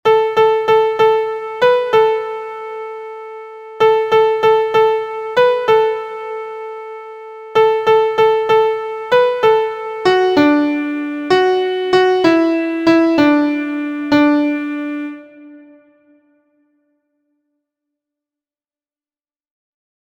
• Origin: Nursery Rhyme
• Key: D Major
• Time: 6/8
• Form: AABC
• Pitches: beginners: Do Re Mi So La – pentatonic scale